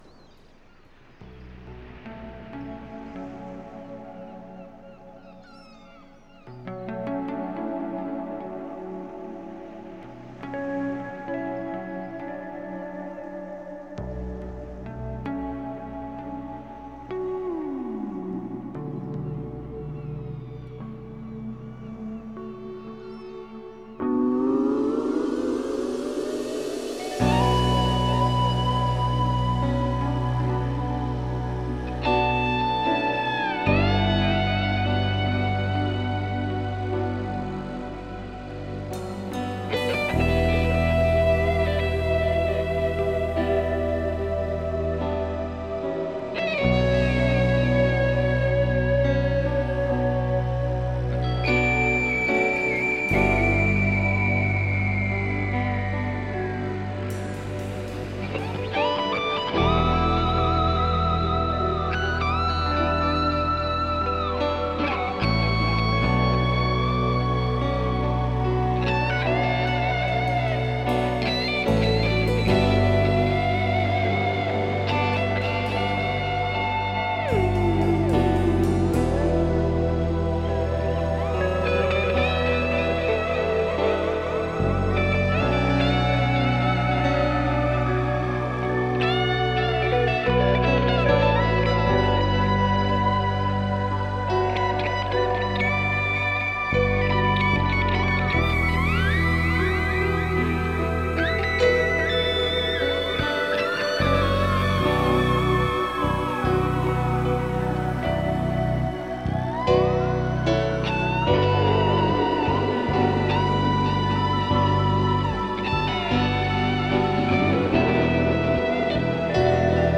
Это музыка под сон.